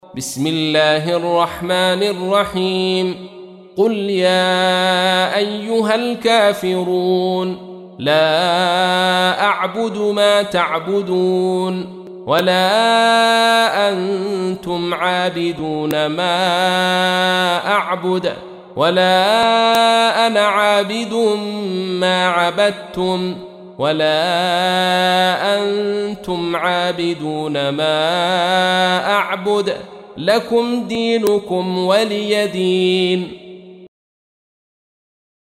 تحميل : 109. سورة الكافرون / القارئ عبد الرشيد صوفي / القرآن الكريم / موقع يا حسين